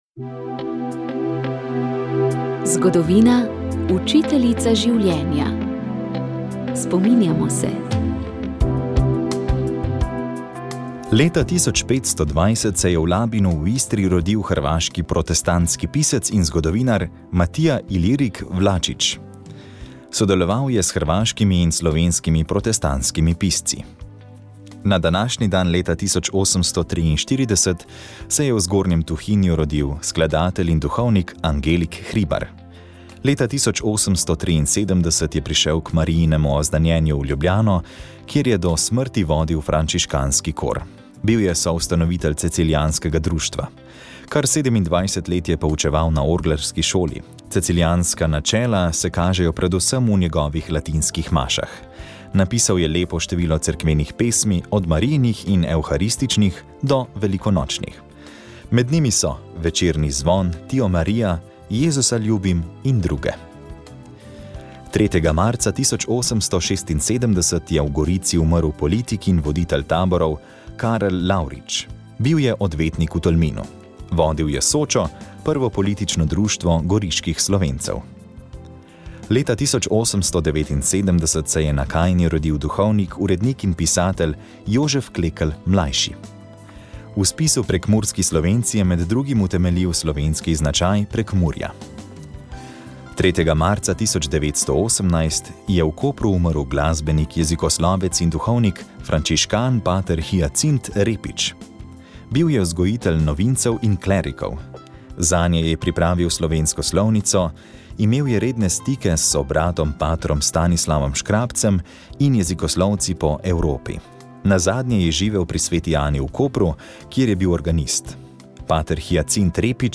Slovenska akademija znanosti in umetnosti je ob stoti obletnici rojstva akademika Alojza Rebule pripravila znanstveni posvet z naslovom Alojz Rebula: Slovenec med zgodovino in nadčasnim.